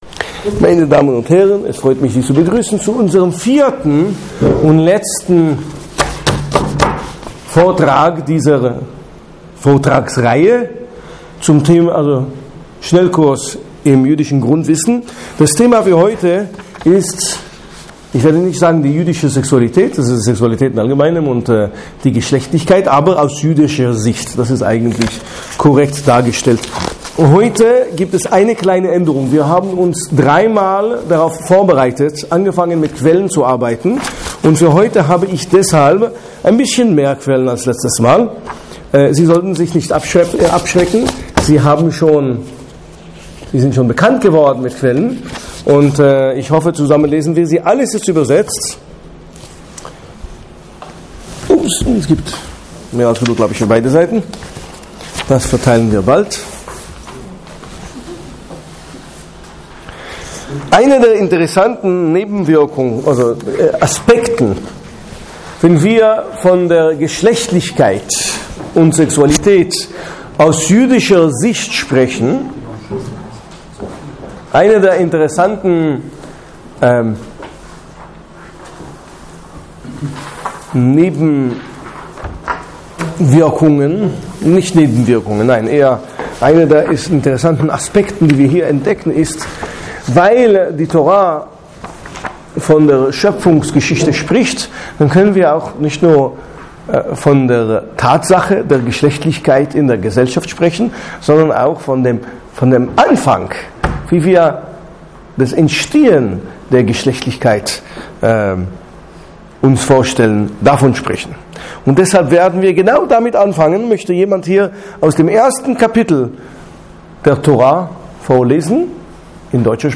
Diese Themen stehen im Mittelpunkt des zweiten Vortrages unseres Schnellkurses im jüdischen Grundwissen.